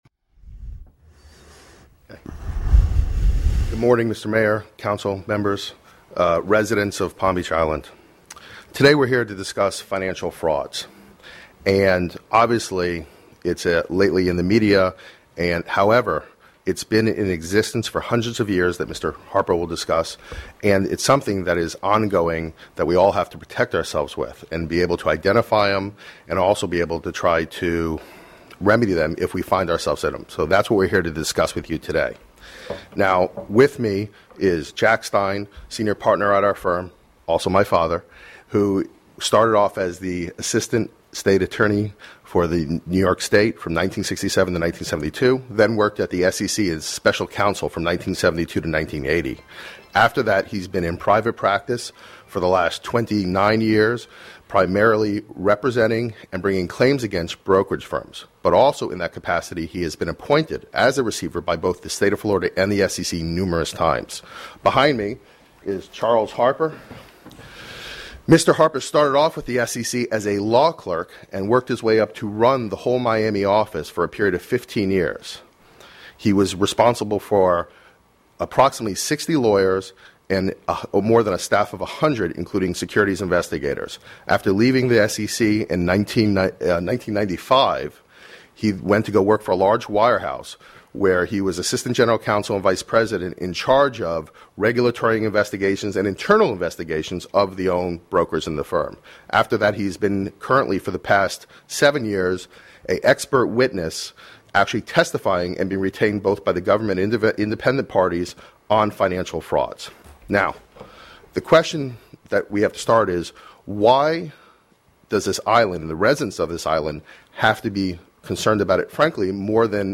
presentation at the Town of Palm Beach Public Safety Committee meeting on January 16, 2009